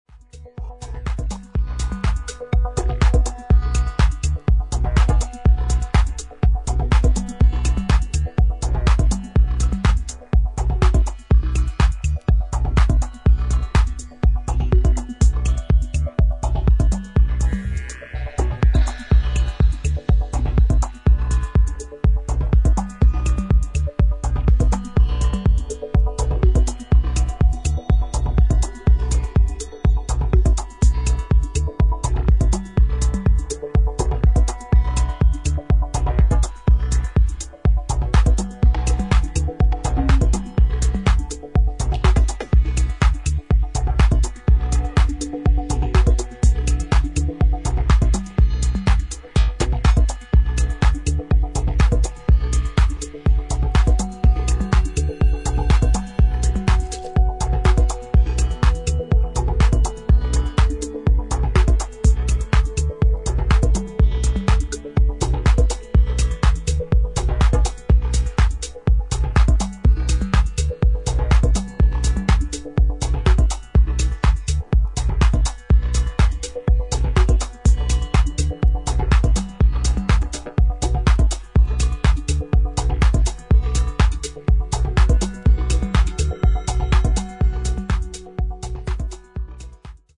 オーガニックなサウンドのシンセ・リードが曲全体を彩る作品となっています